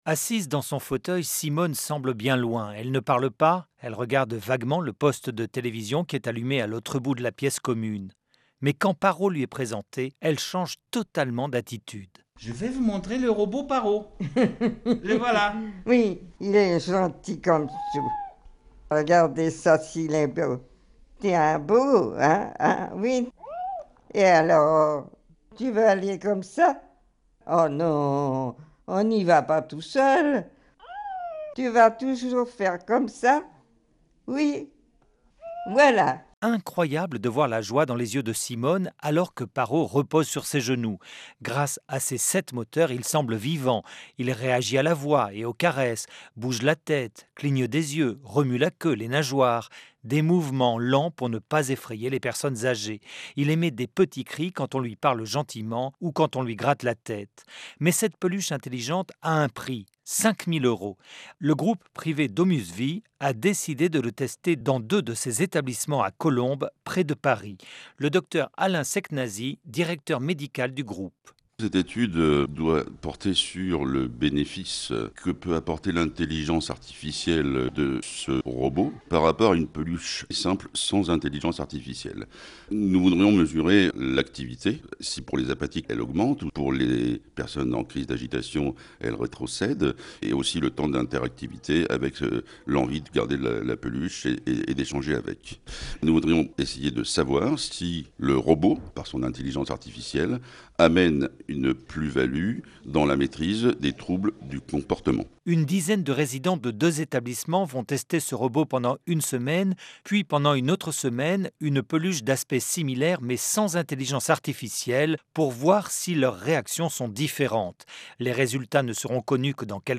Reportage France Info dans une résidence Domus VI
Reportage audio France Info